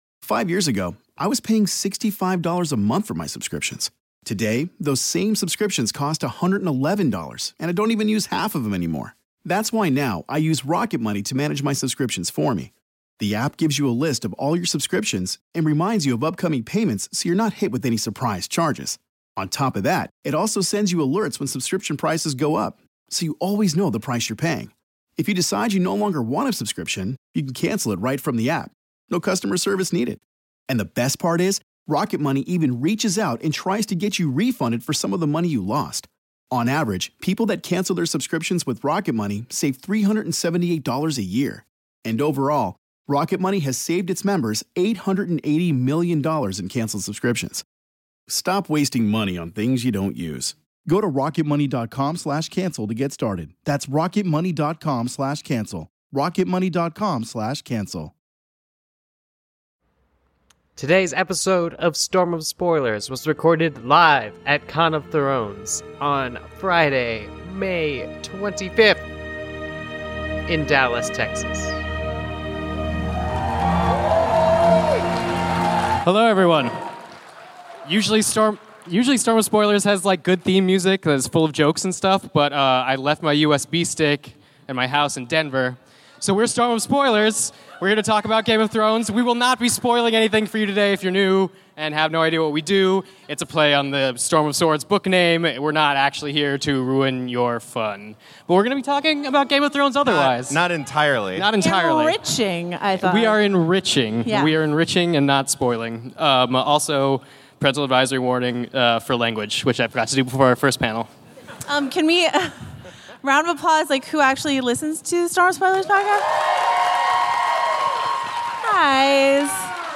This week, it's a STORM OF SPOILERS LIVE SHOW (Spoiler-Free Edition!). Recorded on the main stage at the 2nd Annual Con of Thrones held this year in Dallas, Texas.